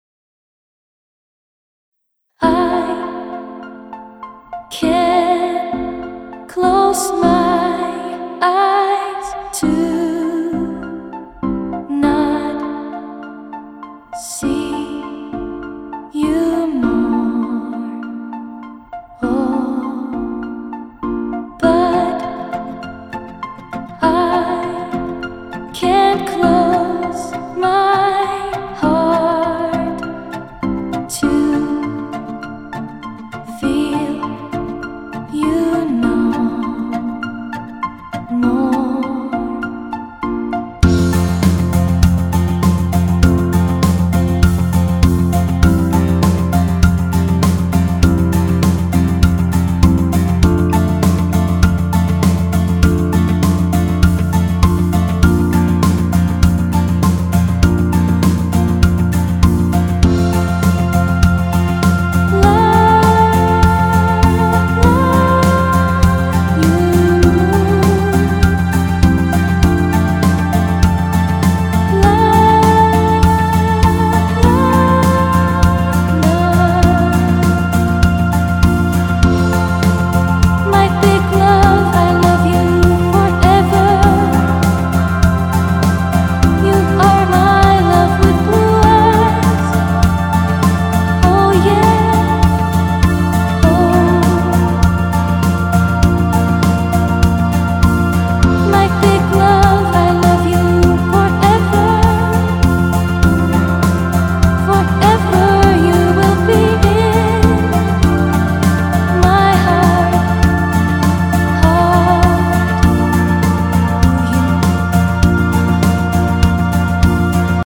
Der I - V - VI - IV - Mainstream - Popsong - Contest
Komisch, hier bleibt der Bass auf einem Ton aber die Akkorde fühlen sich trotzdem gemäß der Vorgabe an, gut unterscheidbar. Dass die Gesangsmelodie bei der V noch den akkordfremden Ton singt, sorgt schon ein wenig für Reibung, musste ich mich erst dran gewöhnen, aber dann gings. Schöne Stimme, die da singt!